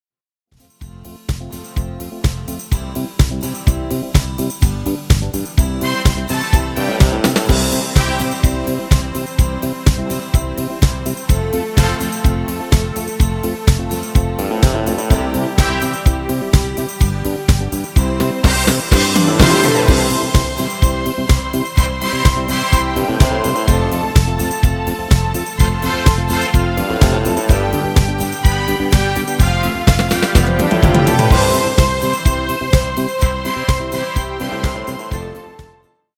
Demo/Koop midifile
Genre: Nederlands amusement / volks
- Géén vocal harmony tracks
Demo = Demo midifile